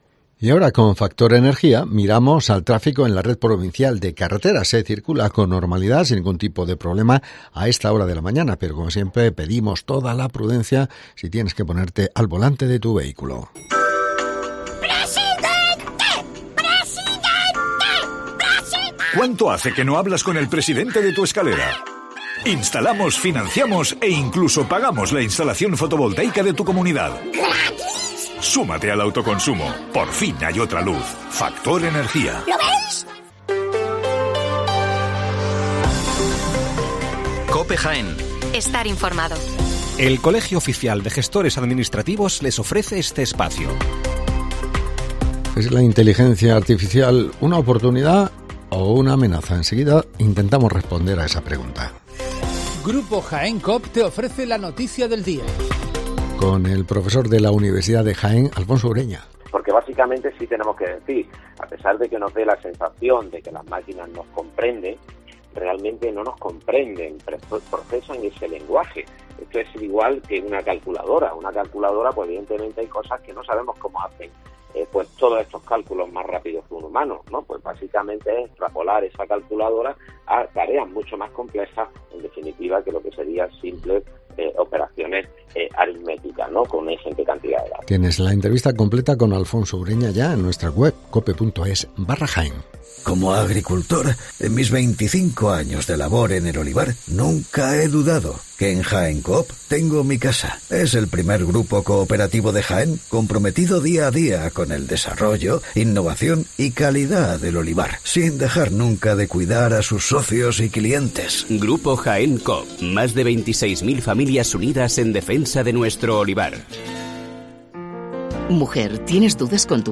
Las noticias locales